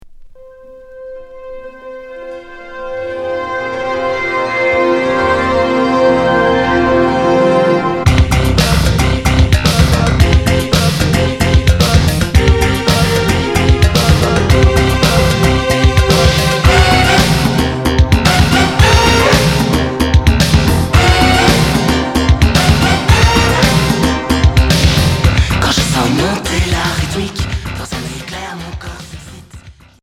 New wave